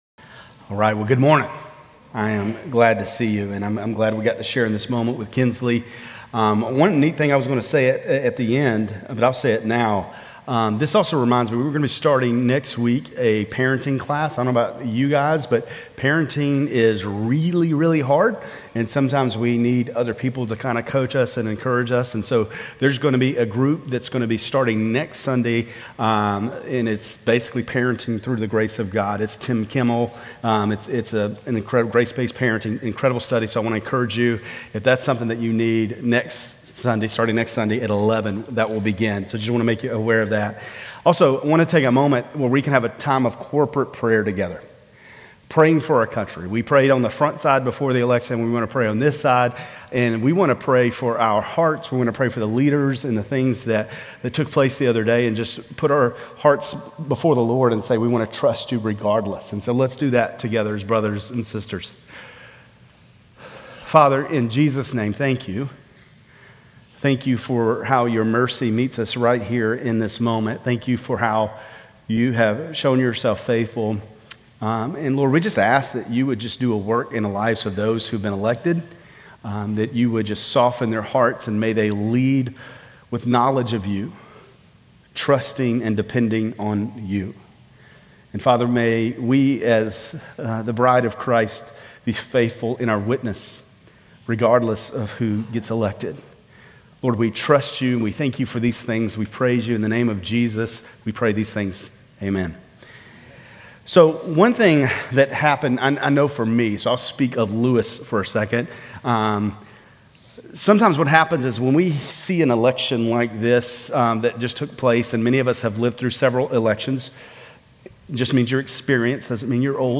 Grace Community Church Lindale Campus Sermons Gen 25:29-34, Gen 27:1-41 - Jacob and Essau Birthright and Blessing Nov 10 2024 | 00:25:40 1x 00:00 / 00:25:40 Subscribe Share RSS Feed Share Link Embed